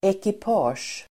Uttal: [ekip'a:sj]